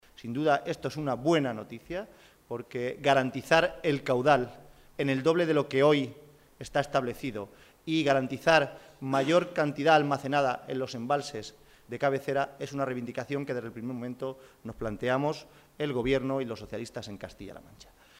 Momento de la rueda de prensa celebrada en la sede del PSOE de Albacete